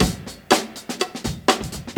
123 Bpm Breakbeat Sample A# Key.wav
Free drum groove - kick tuned to the A# note. Loudest frequency: 2540Hz
123-bpm-breakbeat-sample-a-sharp-key-pIL.ogg